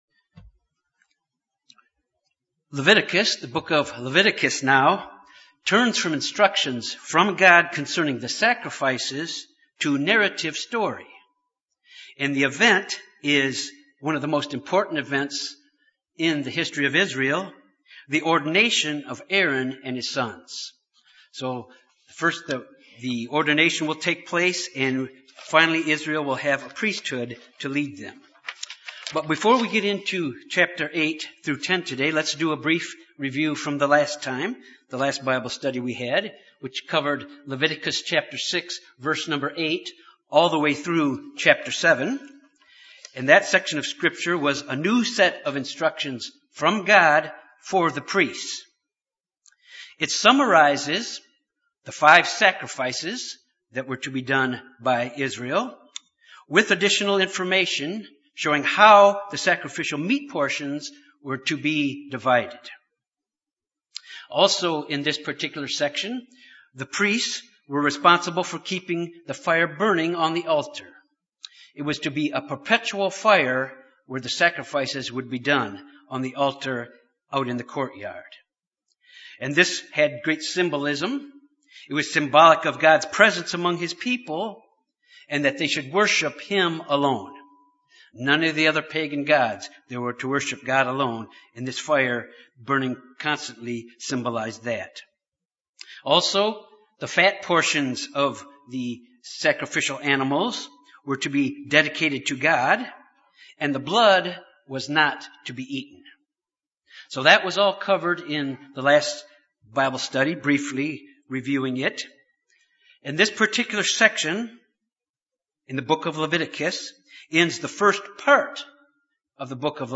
Sermons
Given in Little Rock, AR